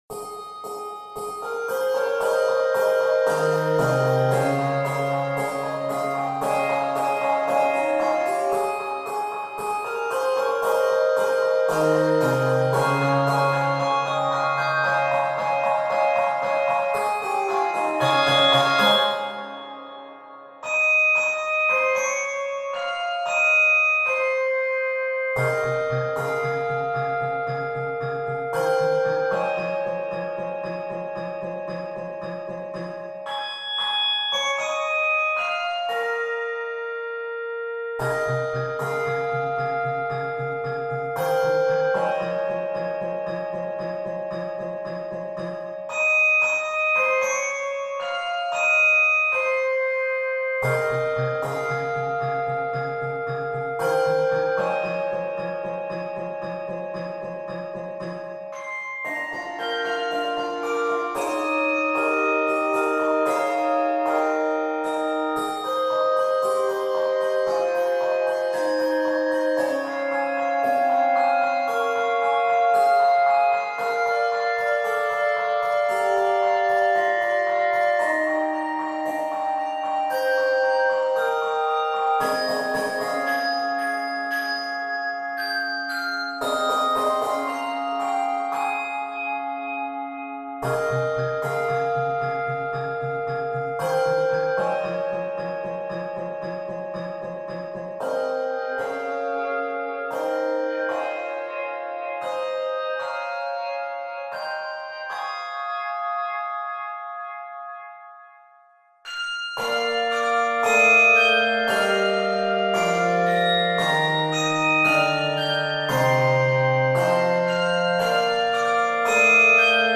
handbell music